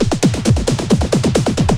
02_21_drumbreak.wav